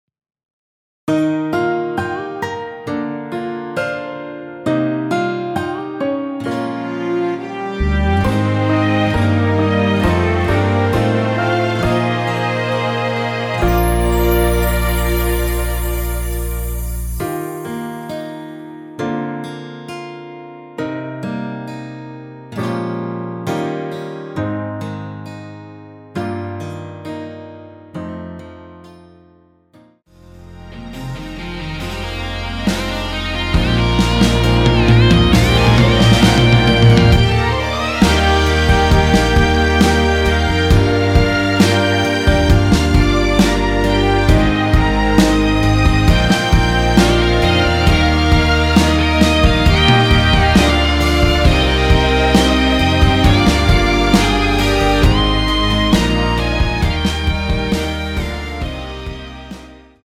Eb
앞부분30초, 뒷부분30초씩 편집해서 올려 드리고 있습니다.
중간에 음이 끈어지고 다시 나오는 이유는